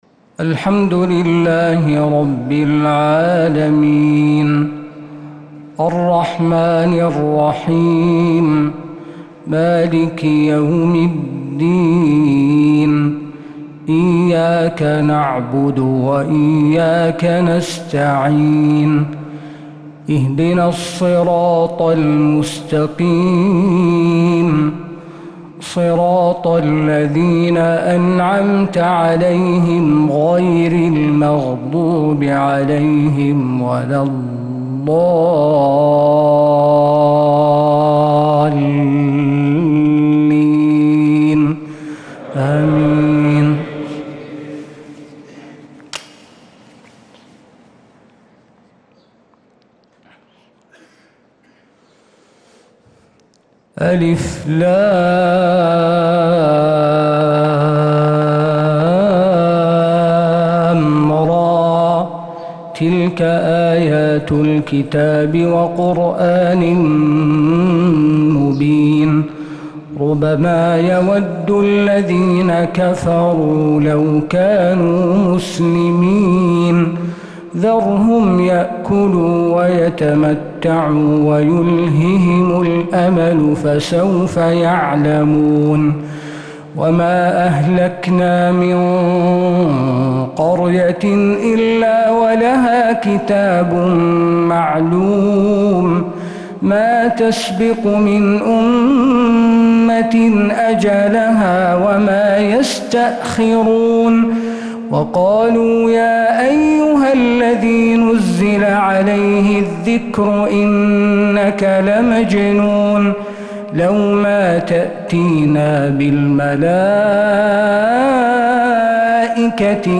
فجر الثلاثاء 5-8-1446هـ فواتح سورة الحجر 1-48 | Fajr prayer from Surat al-Hijr 4-2-2025 > 1446 🕌 > الفروض - تلاوات الحرمين